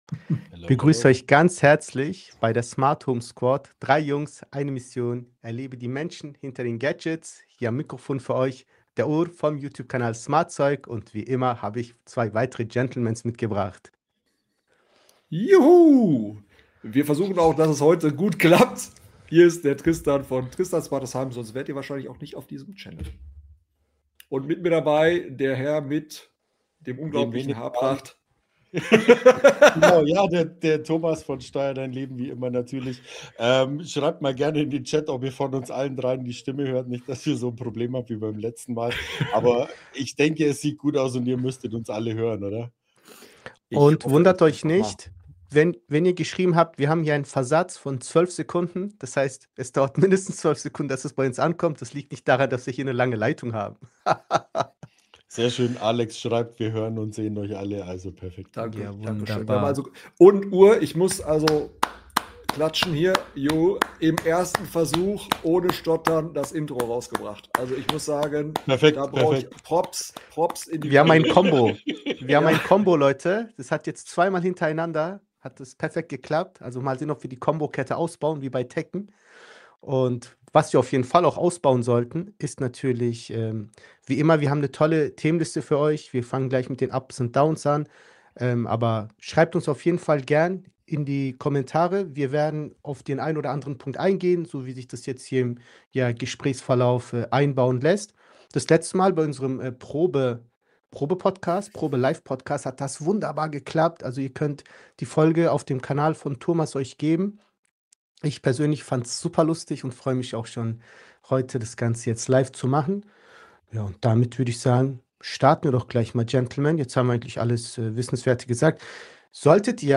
#17 Endlich live! Finanzen, Wie nutzen wir AI?, Frauenquote, Zuschauerfragen ~ Smart Home Squad Podcast
Wir klären LIVE mit unseren Zuschauern, wie man Youttube als Unternehmer führt, wie wir AI während der Content Erstellung nutzen und beleuchten das spannendes Thema Frauenquote!